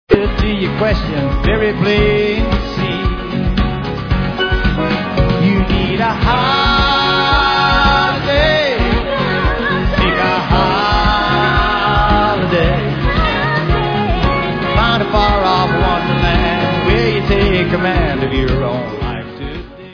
Live
sledovat novinky v kategorii Country